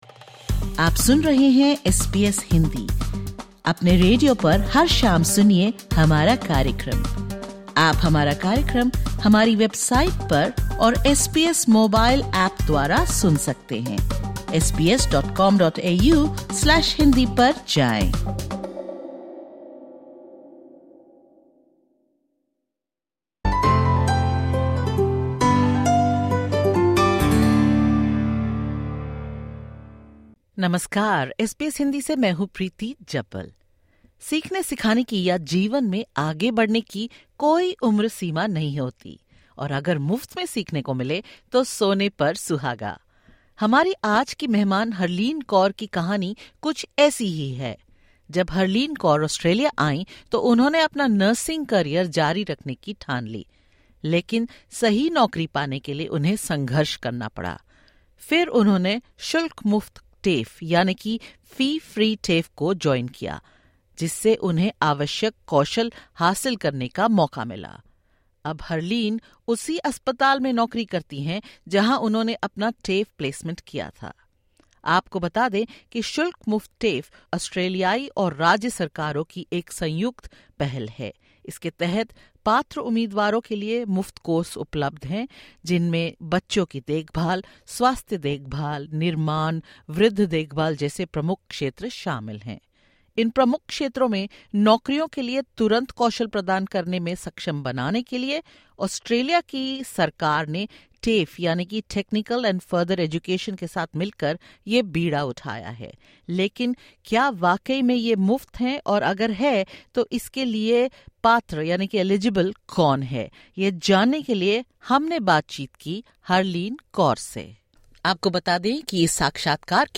LISTEN TO ‘Untapped gem’: Australia eases travel advisory for India's northeast state of Assam after 20 years SBS Hindi 07:04 Hindi Disclaimer: The information given in this interview is of general nature.